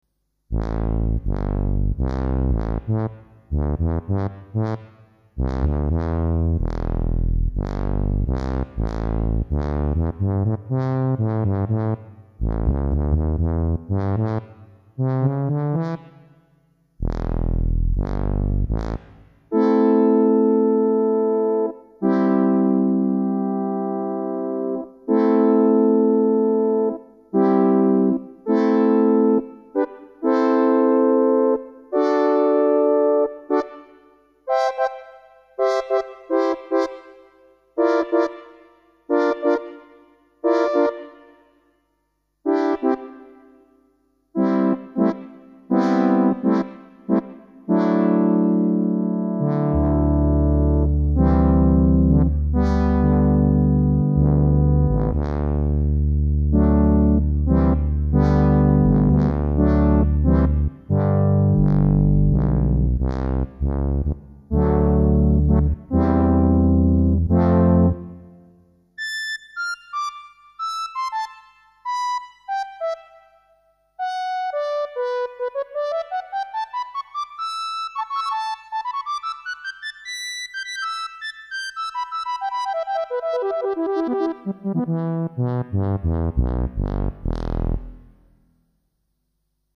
The Yamaha DX7 digital programmable algorithm synthesizer.
The DX7 can make decent analog style sounds.
Brass.mp3